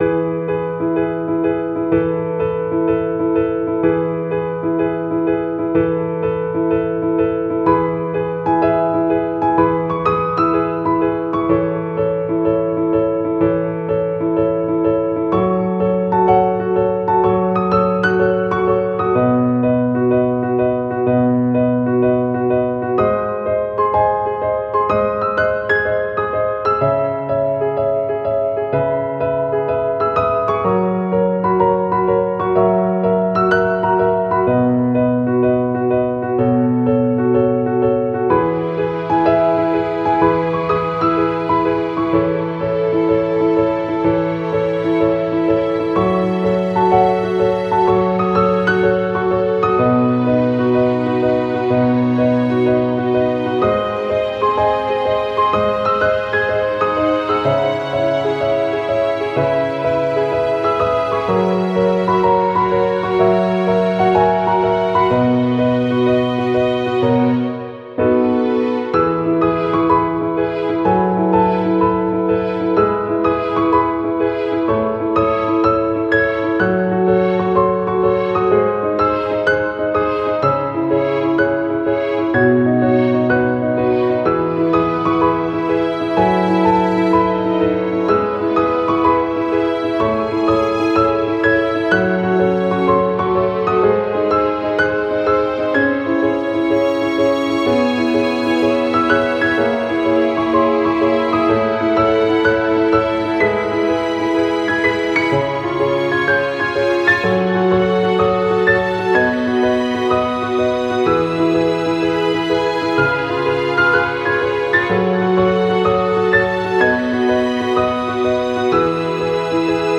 ogg(R) ほのぼの ゆったり ピアノ
開放的なピアノとストリングス。